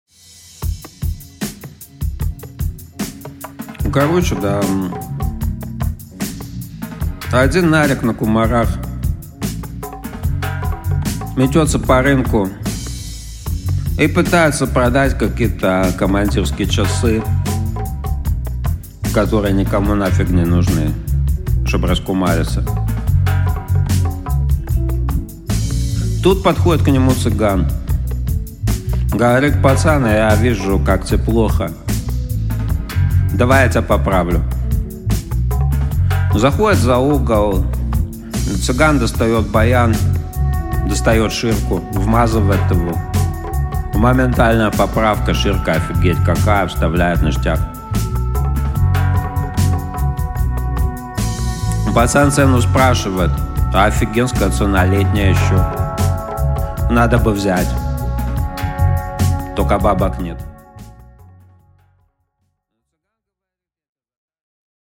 Аудиокнига Случай из жизни | Библиотека аудиокниг